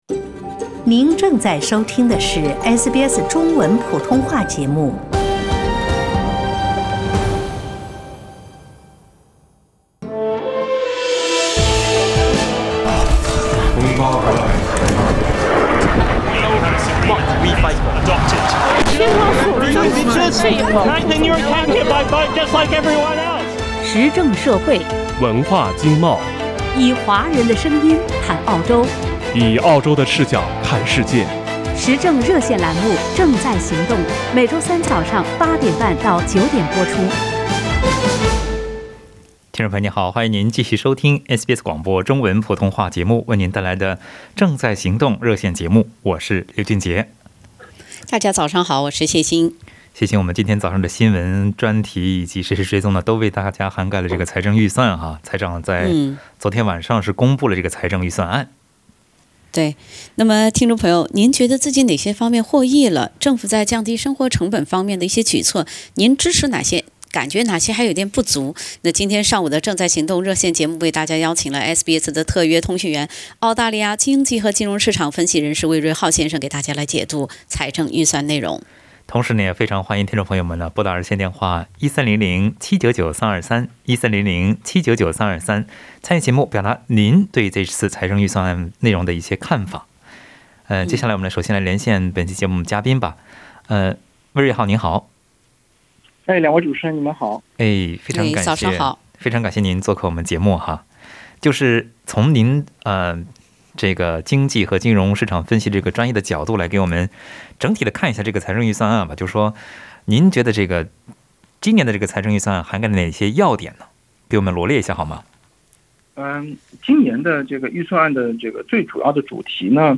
听友们也就预算内容表达了自己的看法。